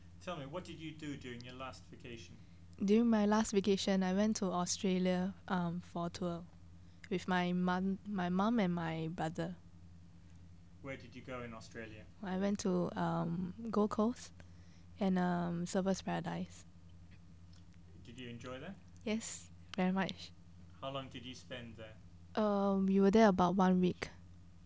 The NIE Corpus of Spoken Singapore English